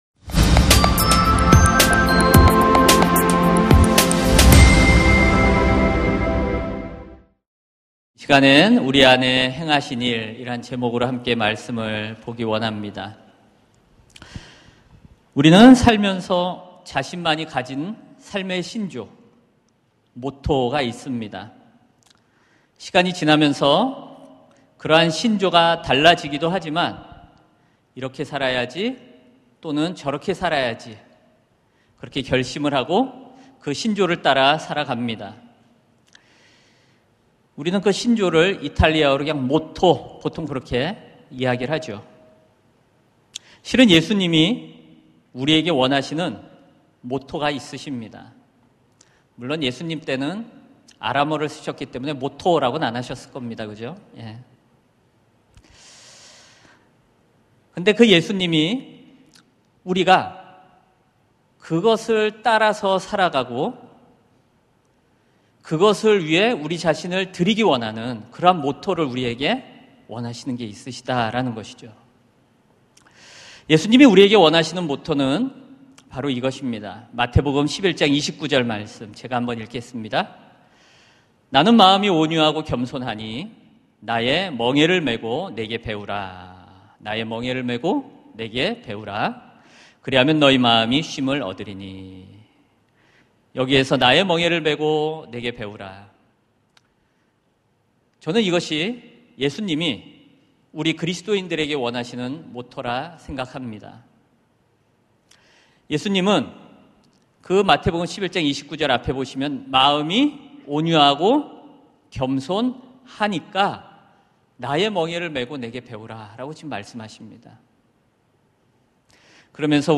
설교 : 금요심야기도회